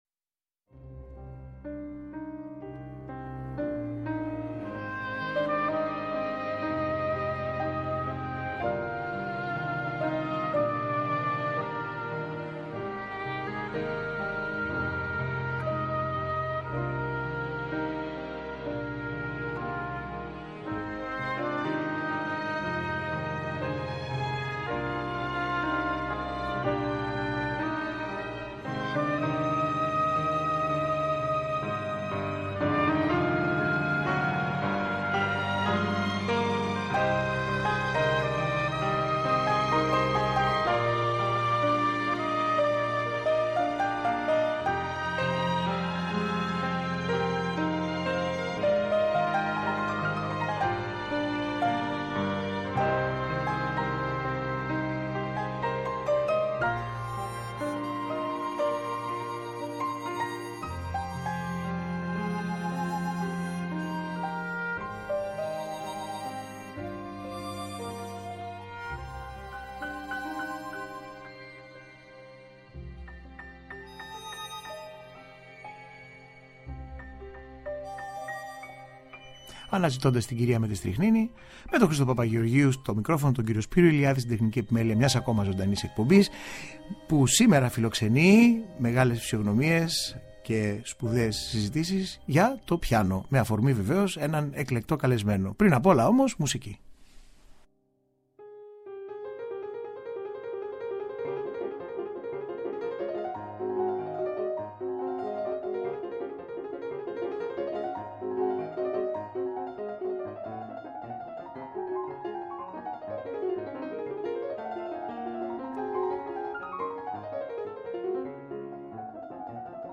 Ο μοναδικός καλλιτέχνης σε μια σπάνια συνέντευξη με αφορμή το ρεσιτάλ του στις 31 Μαρτίου.